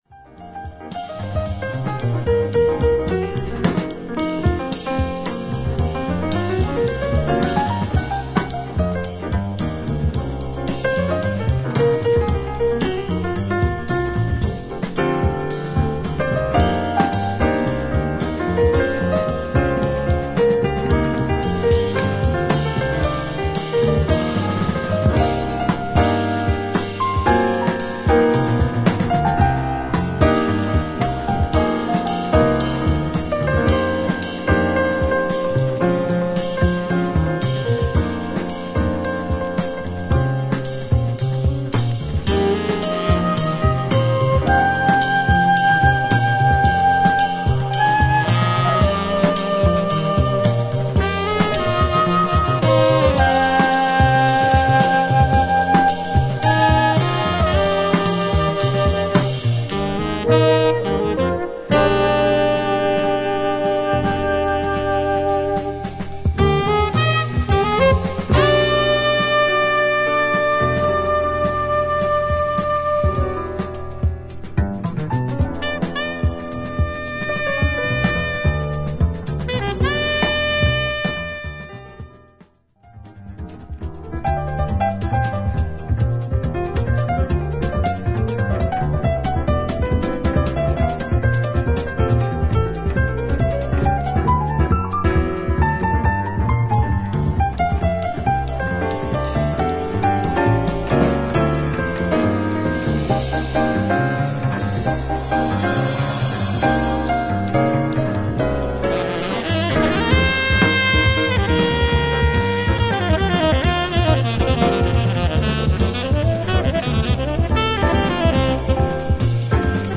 透明感のある優美なコンテンポラリー・ジャズ
アンビエンス感漂う極上のクワイエット・ジャズ